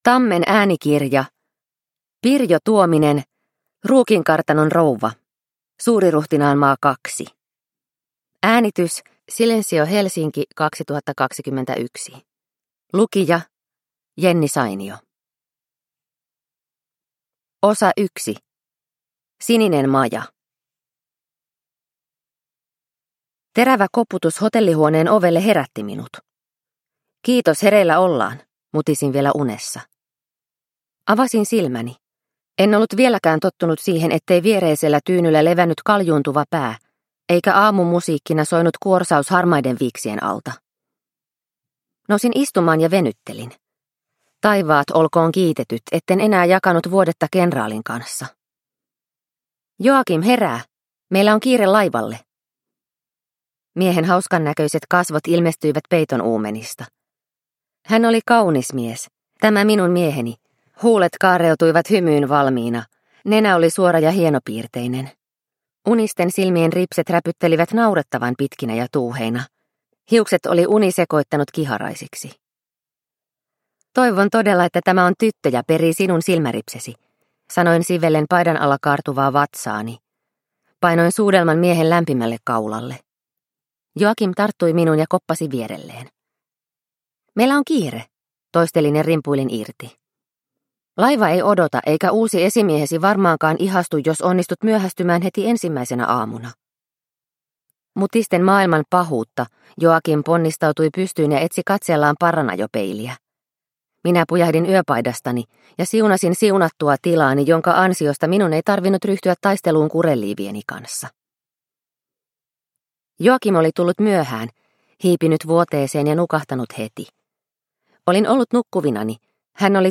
Ruukinkartanon rouva – Ljudbok – Laddas ner